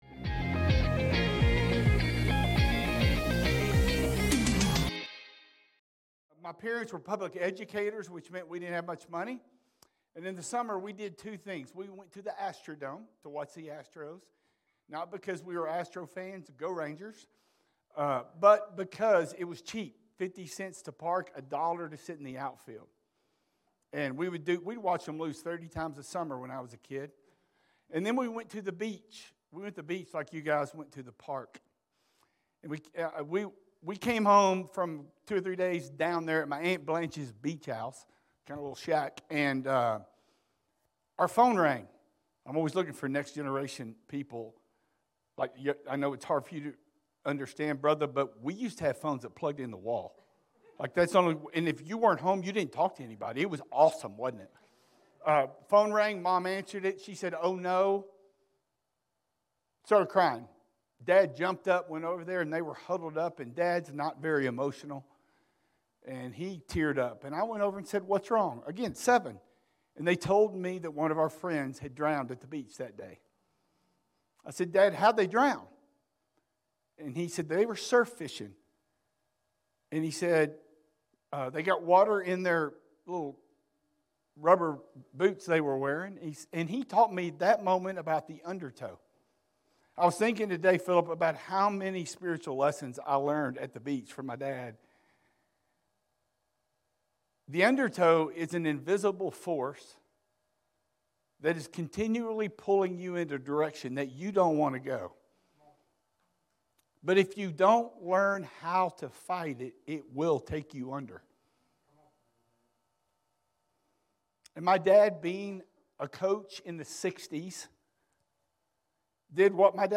In this sermon series we’re discussing the importance of having Living Hope in all areas of life, including mentally.